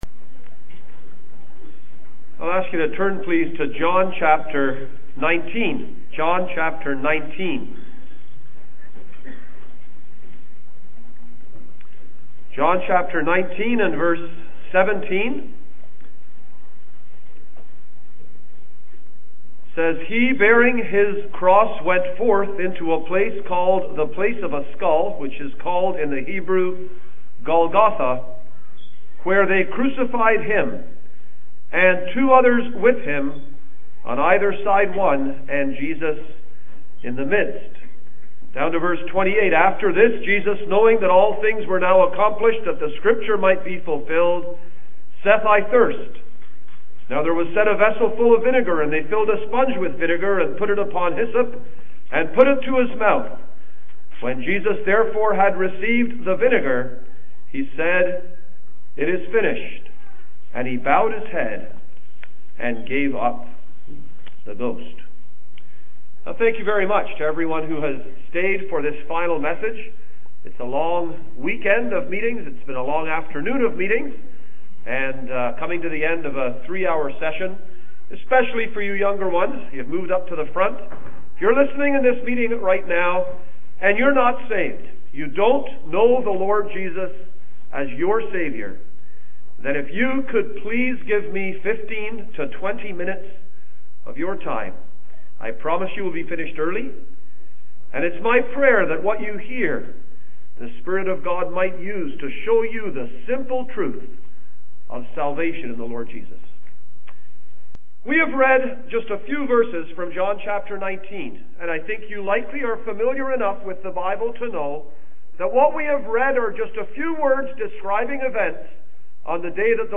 Sunday Gospel
2019 PGH Annual Conference